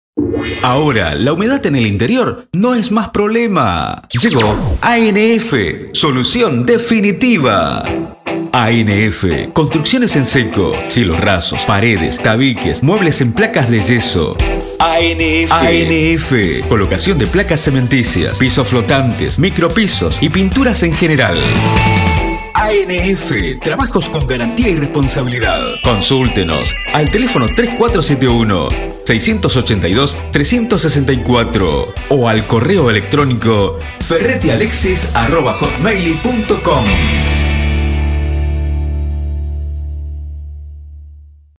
SPOT ANF solución definitiva